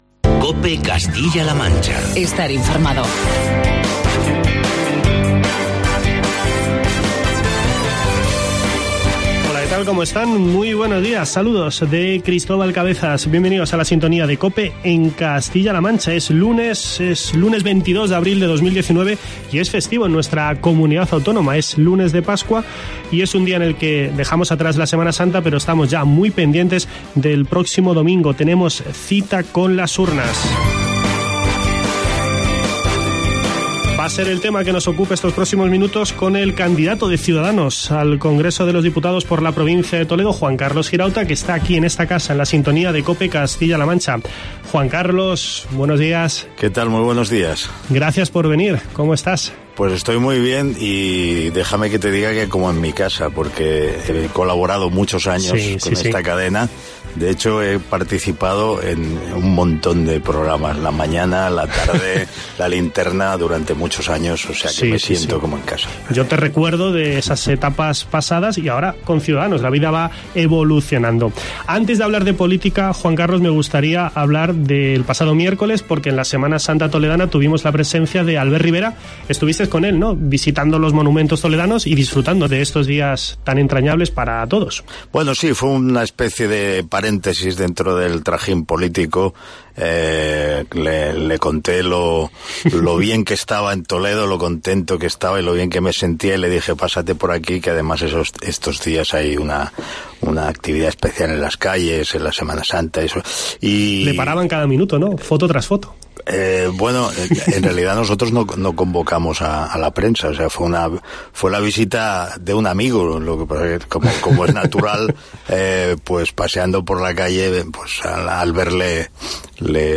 Entrevista con Juan Carlos Girauta